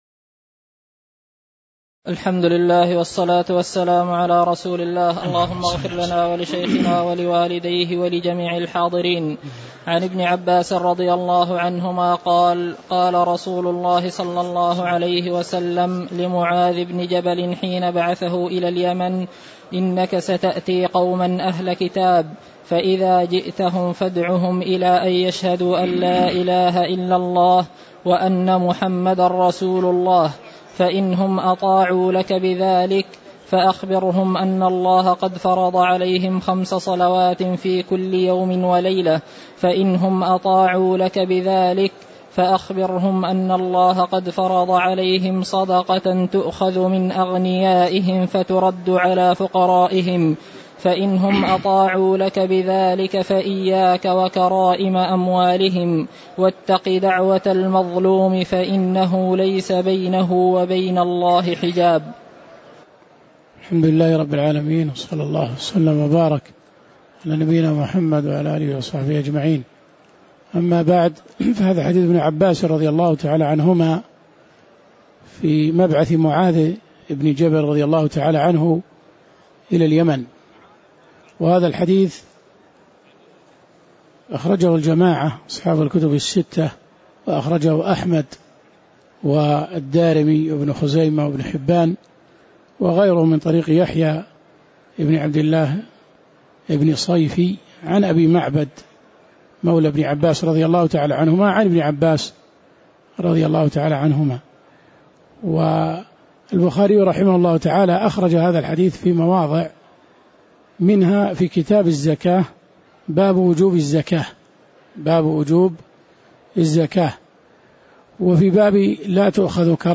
تاريخ النشر ٥ رمضان ١٤٣٩ هـ المكان: المسجد النبوي الشيخ